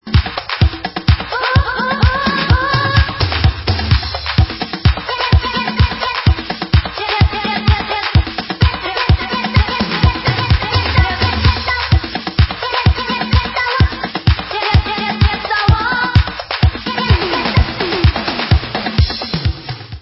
sledovat novinky v oddělení Disco